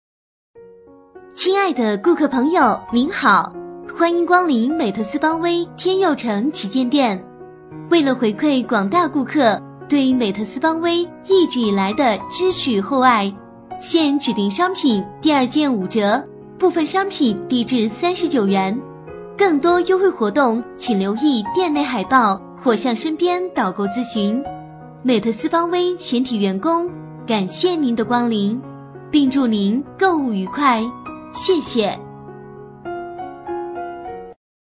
【女23号抒情】店内迎宾
【女23号抒情】店内迎宾.mp3